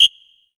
PSWHISTLE.wav